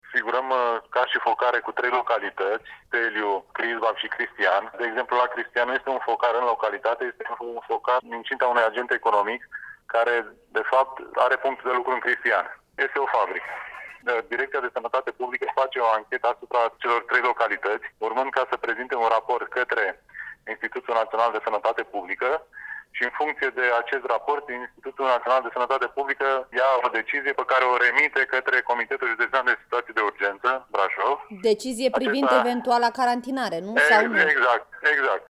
Prefectul județului, Cătălin Văsii, spune la Europa FM că depistarea tuturor contacților e acum aproape imposibilă:
În județul Brașov, există acum trei focare de coronavirus, 28 de persoane fiind depistate pozitiv, după cum explică prefectul județului, la Europa FM.